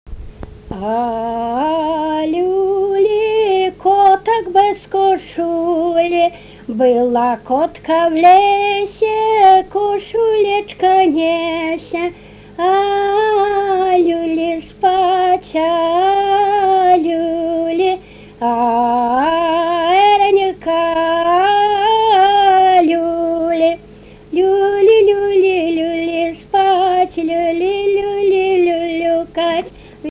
smulkieji žanrai